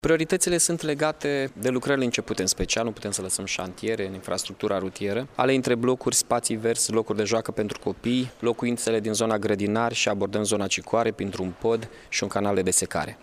Ca urmare a reformei fiscale, bugetul se va ridica la 844 de milioane de lei, a precizat, astăzi, într-o conferinţă de presă, primarul Mihai Chirica.